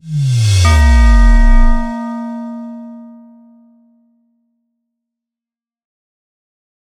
Bell1.ogg